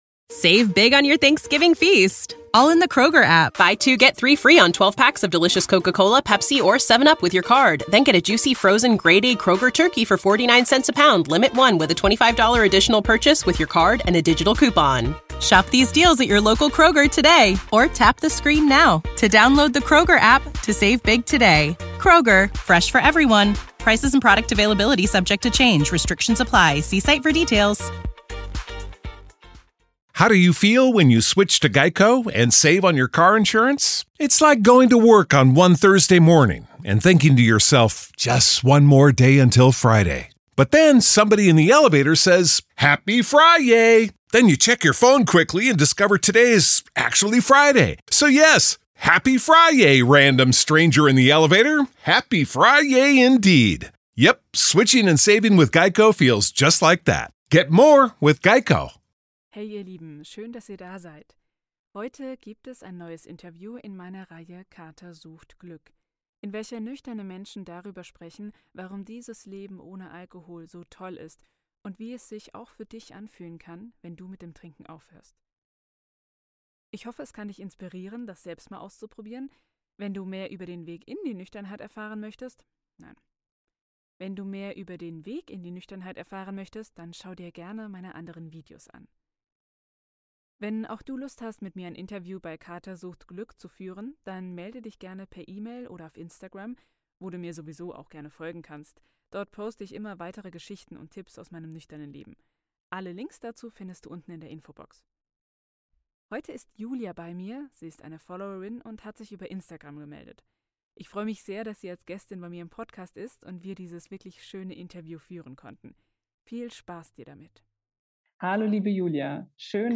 Ich hoffe, das Interview gefällt euch - schreibt mir das gerne mal in die Kommentare!